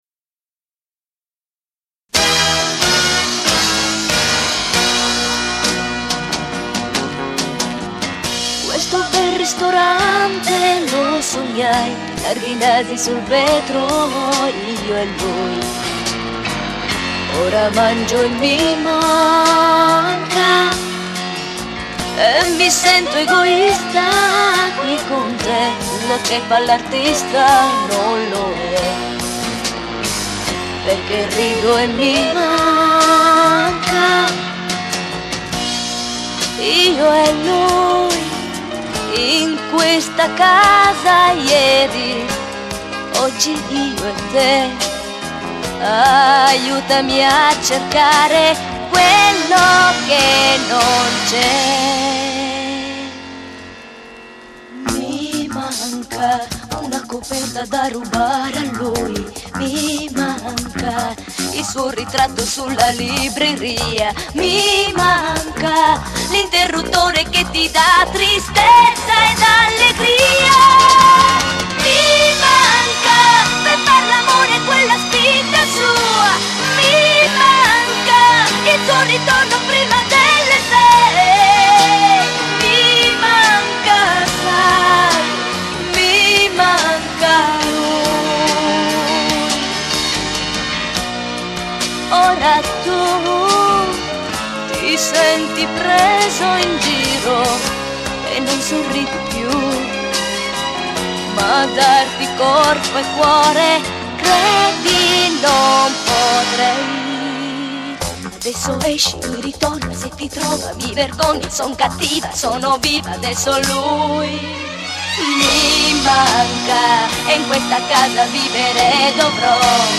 Basso
Batteria
Chitarra Acustica
Tastiere